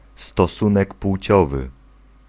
Ääntäminen
IPA: /stɔˈsũnɛk ˈpw̥ʨ̑ɔvɨ/